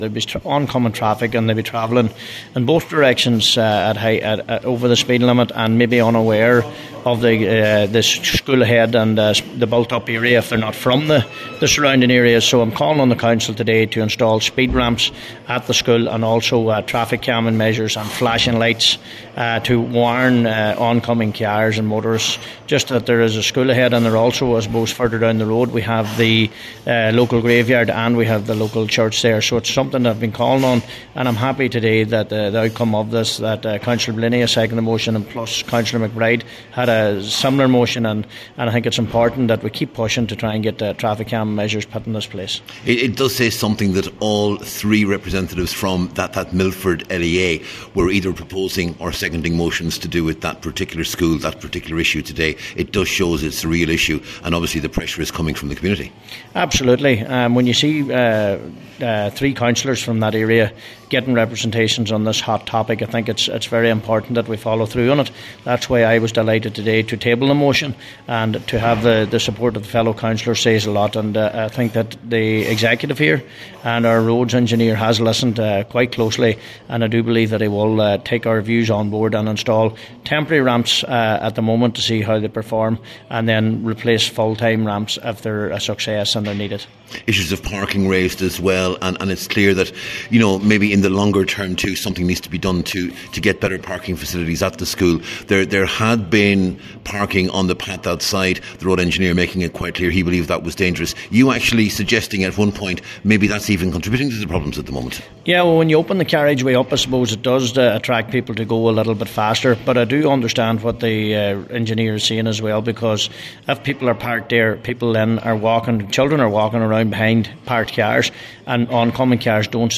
Cllr John O’Donnell acknowledges that, but says the fact that all of the local councillors were approached is significant………..